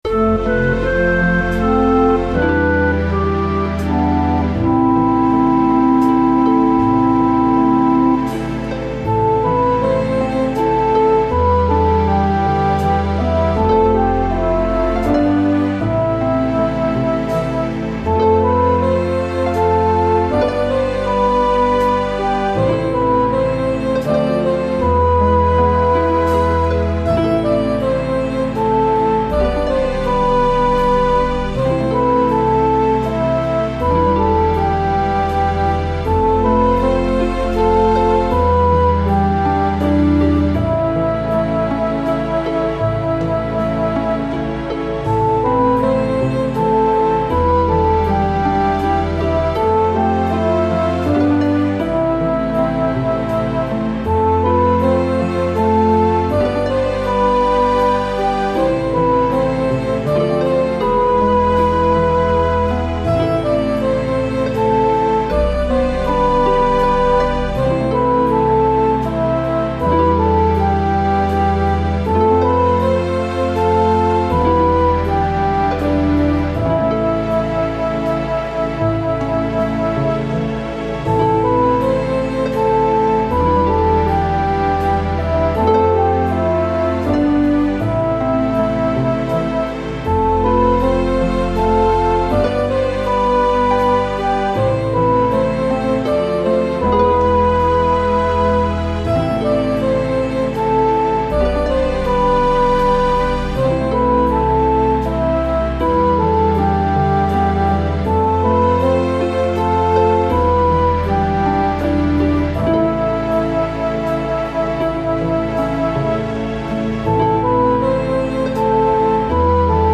The very last line of the last verse is followed by an echo.